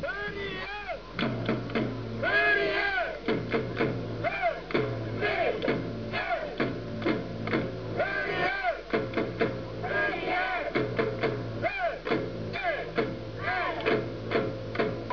オ ー レ ヨ コ ハ マ の 応 援
「オーレ ヨコハマ」の応援は、基本的に太鼓１つでも出来る「声」による簡単なパターンになっています。